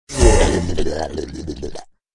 world-of-warcraft-polymorph-turtle_25879.mp3